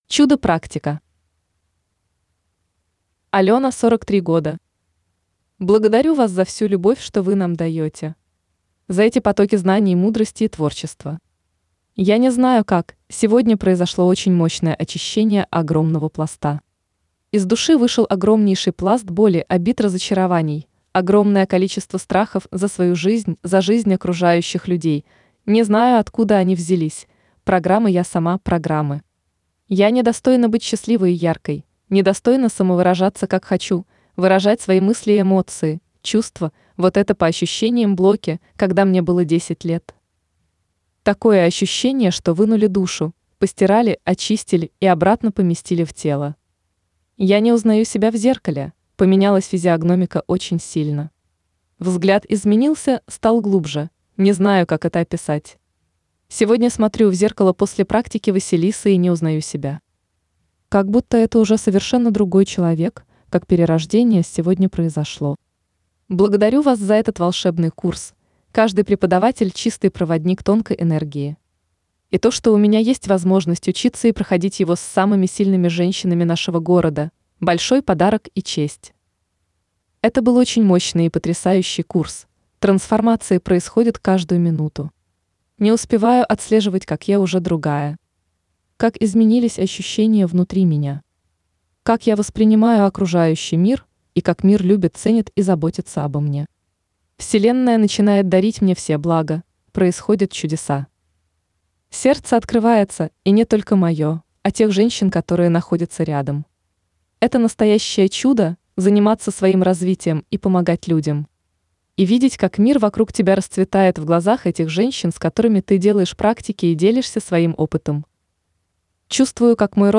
Жанр: Аудио книга.